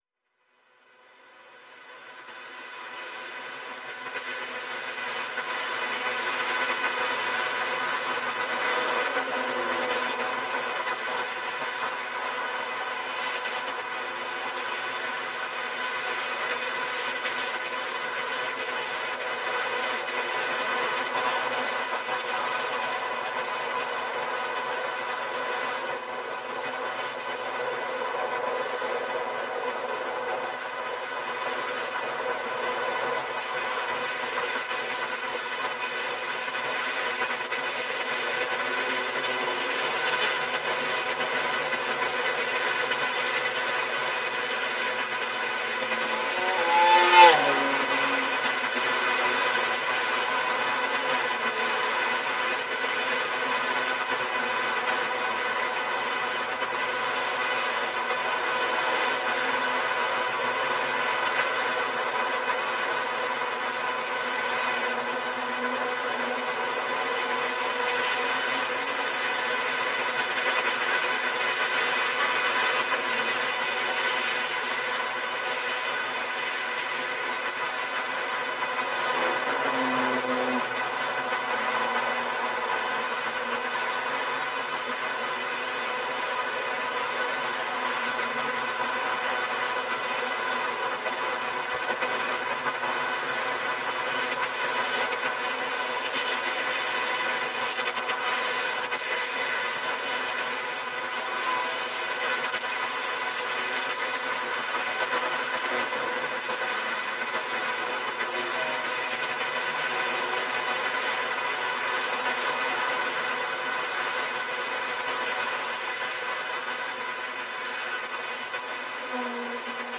electroacoustic music
8-Kanal-Audio
Location: A 81 (Untergruppenbach)